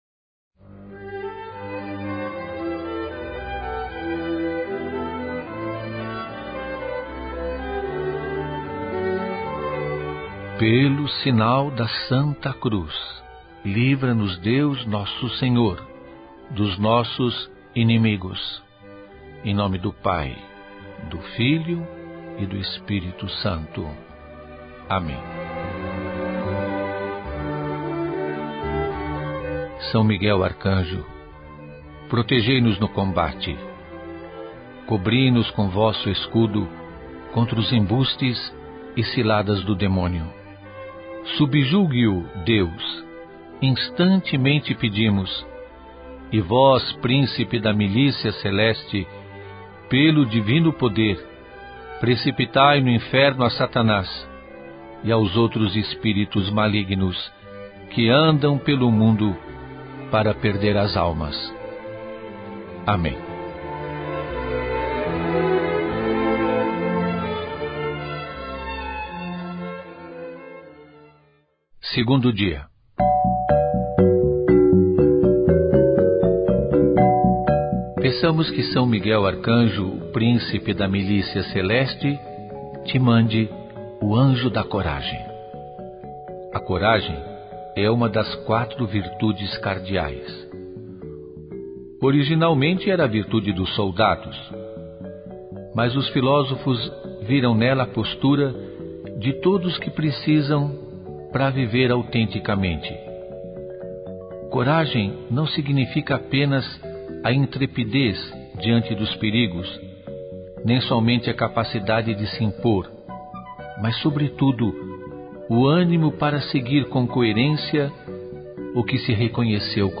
Neste mês em que se celebra a festa do padroeiro, participe da Novena em honra a São Miguel Arcanjo, baseada no livro “50 Anjos para a Alma” do monge Anselm Grun, na voz do Pe. Julio Lancellotti.